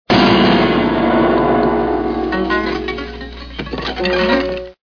Cartoon Piano Crash Sound Effect Free Download
Cartoon Piano Crash